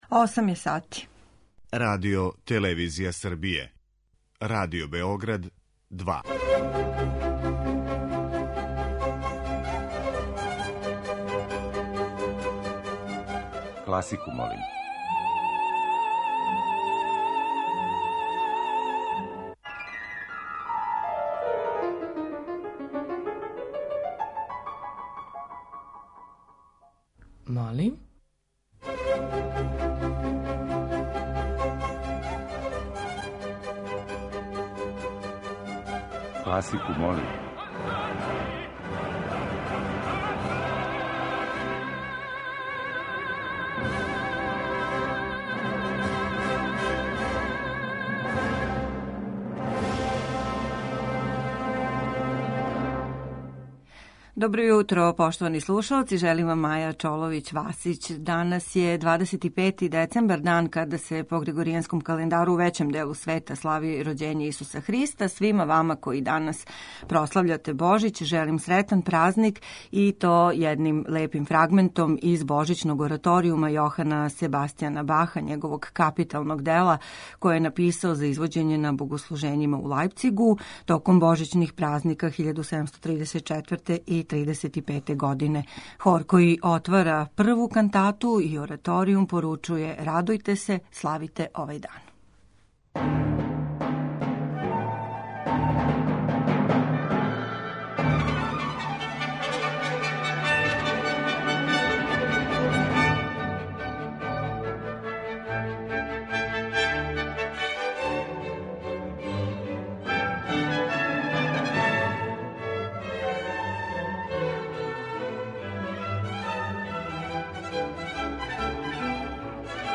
Предпразнични циклус емисија Класику молим, уједно и последњи у коме бирамо хит недеље, обојиће музика инспирисана Божићем.